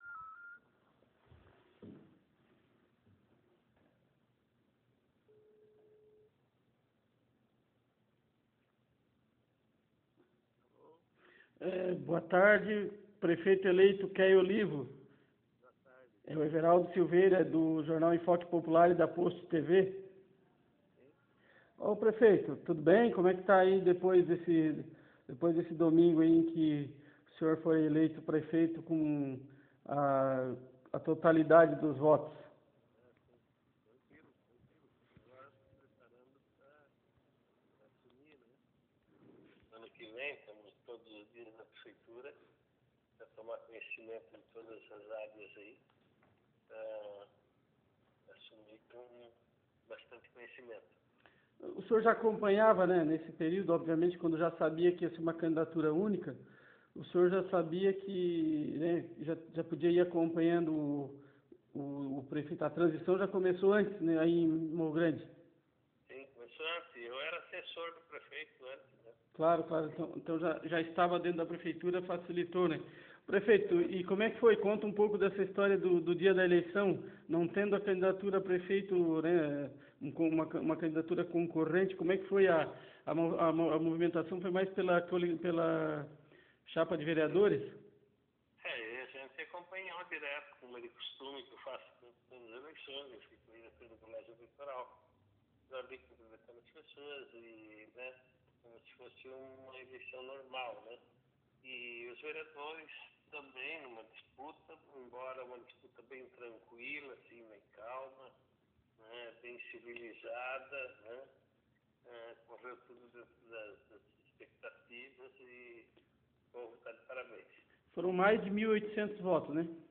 entrevista-Keio-MG.mp3